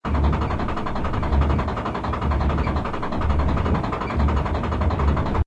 CHQ_FACT_stomper_raise.ogg